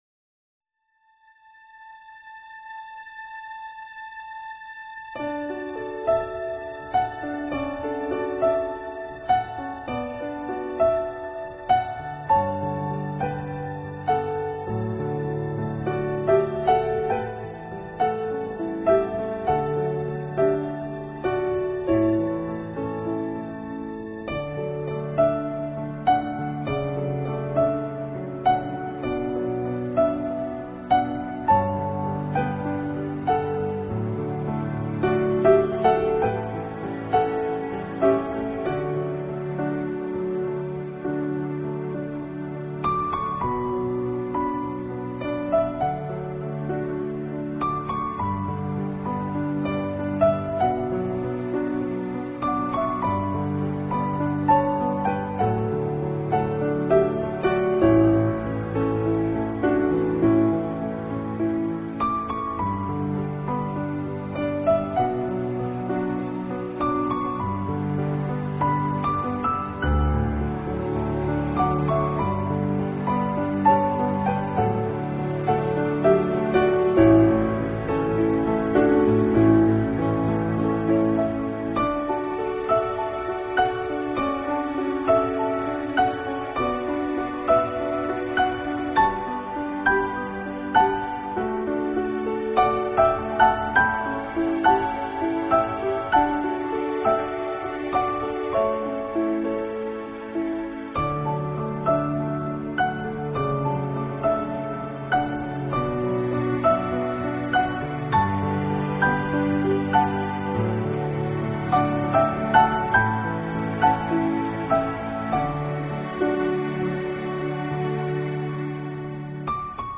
【纯乐钢琴】
专辑的录音相当细腻讲究，钢琴的高音呈现出晶莹亮丽的质感，音场动态宽